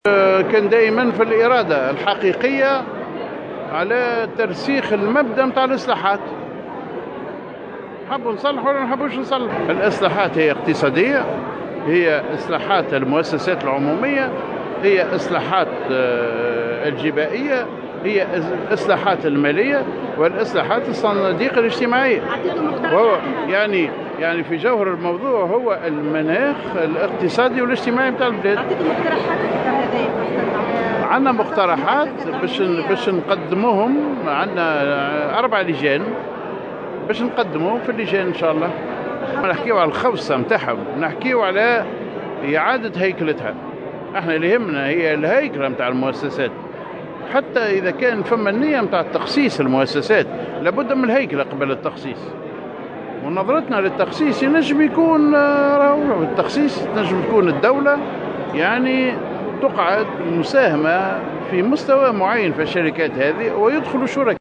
وقال في تصريح لمراسلة "الجوهرة أف أم"، على هامش ندوة وطنية حول الاصلاحات العمومية، إن الأهم من الخوصصة هو إعادة هيكلة المؤسسات وذلك قبل الحديث عن الخوصصة.